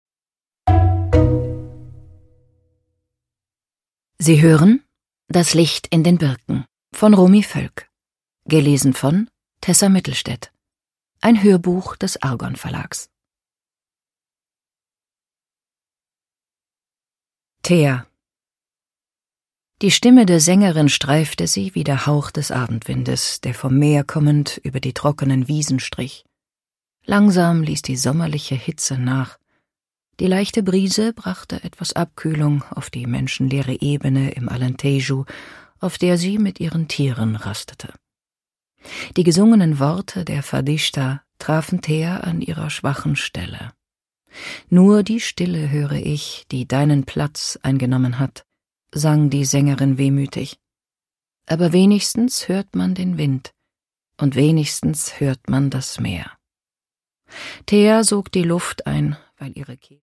Produkttyp: Hörbuch-Download
Gelesen von: Tessa Mittelstaedt
Als Hörbuchsprecherin weiß sie Temperamente und Stimmungen von nordisch-kühl bis herzlich gekonnt auszudrücken.